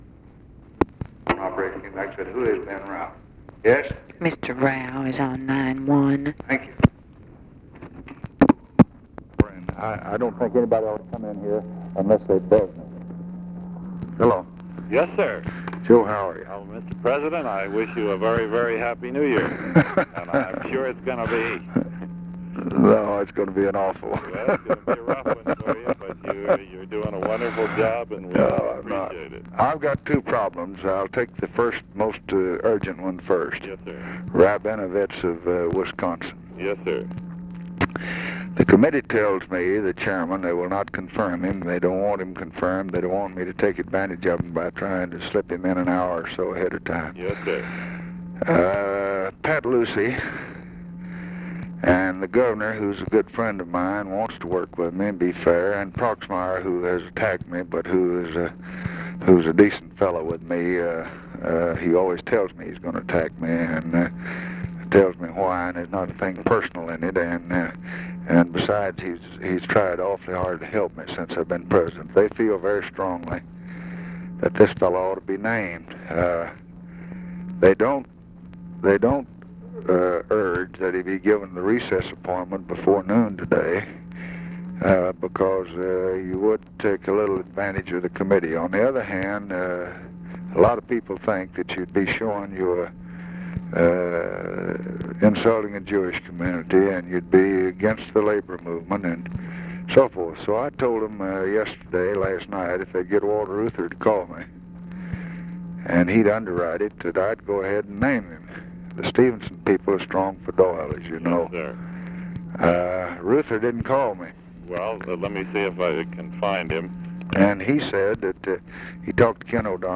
Conversation with OFFICE CONVERSATION, January 7, 1964
Secret White House Tapes